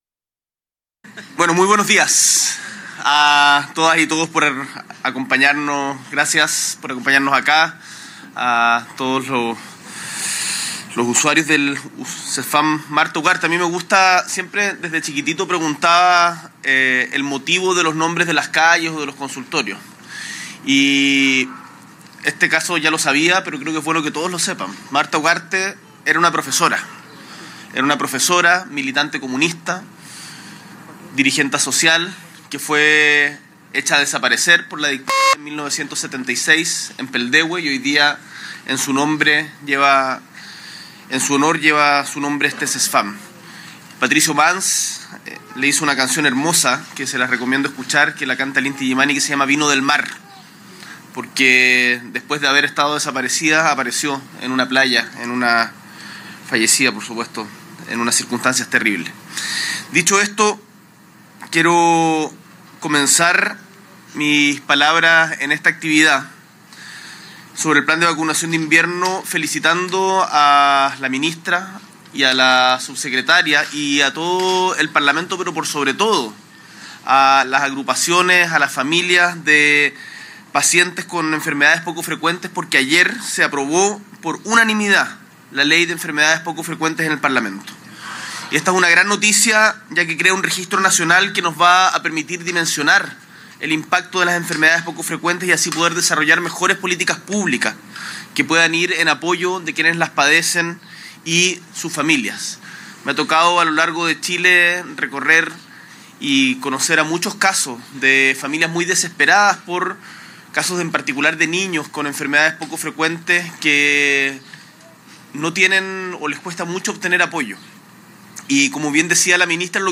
S.E. el Presidente de la República, Gabriel Boric Font, junto a la Ministra de Salud, Ximena Aguilera, y la alcaldesa de Quilicura, Paulina Bobadilla, visita el Centro de Salud Familiar (Cesfam) Marta Ugarte en el marco de la Campaña de Vacunación e Inmunización 2025